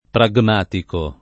pragmatico [ pra g m # tiko ] → prammatico